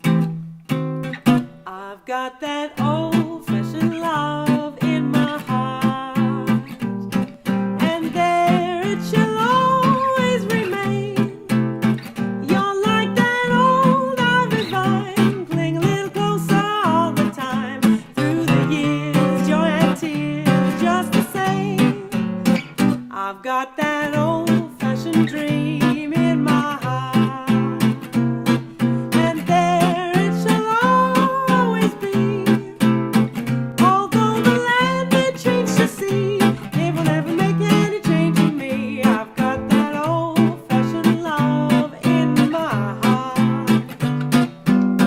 chant, violon
guitare, chant
piano, chant
contrebasse.